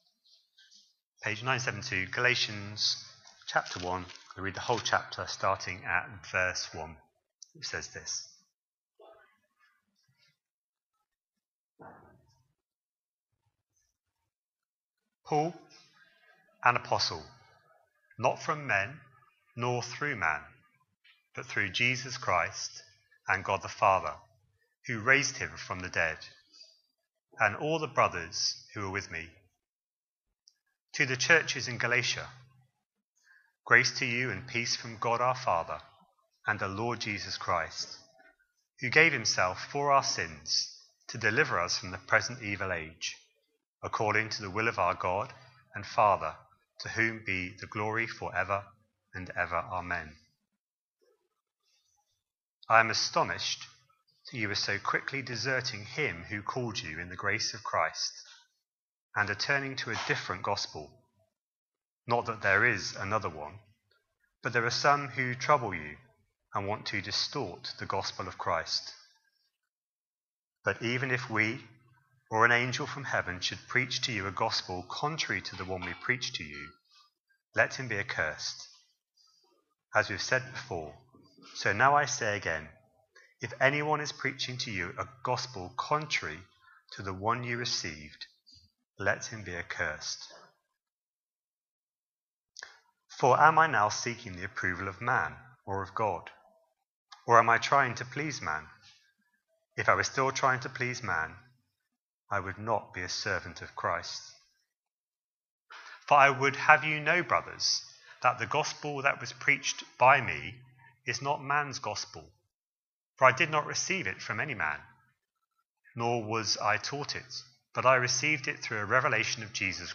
A sermon preached on 1st June, 2025, as part of our Galatians series.